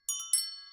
Footsteps
bells4.ogg